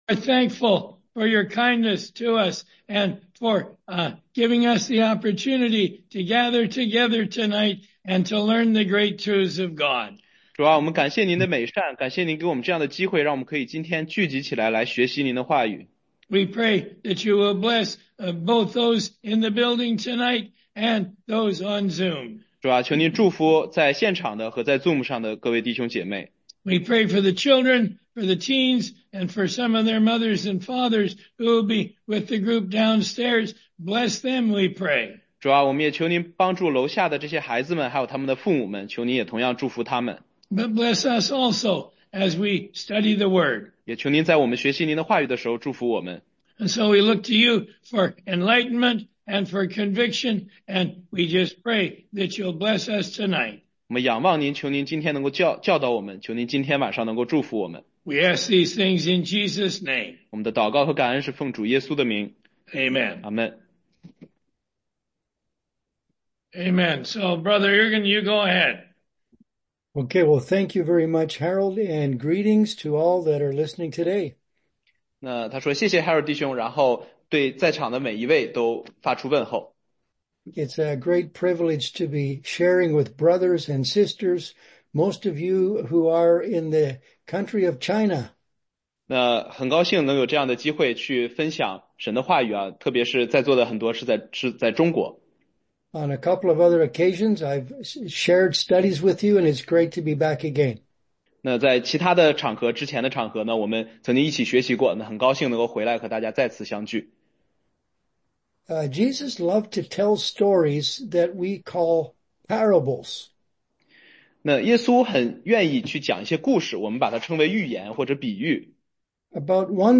中英文查经